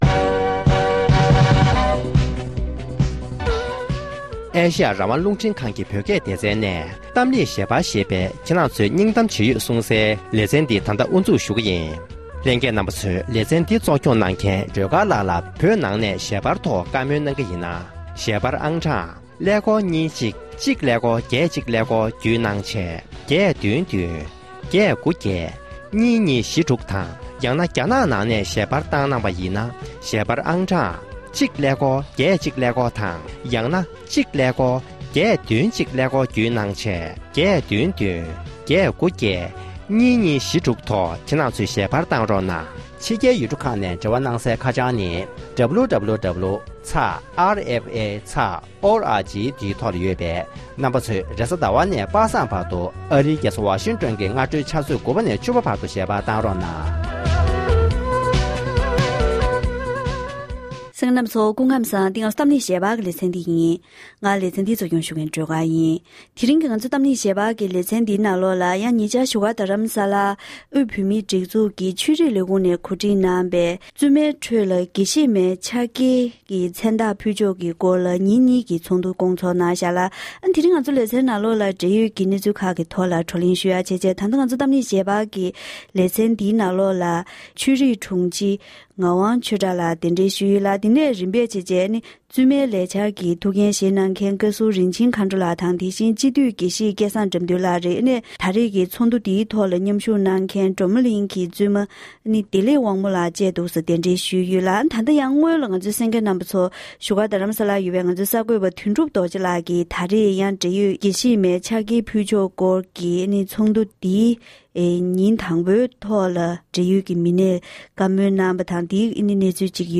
༄༅༎དེ་རིང་གི་གཏམ་གླེང་ཞལ་པར་གྱི་ལེ་ཚན་ནང་དུ།